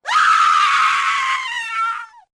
Screaming Female Death 2 Sound Effect Free Download
Screaming Female Death 2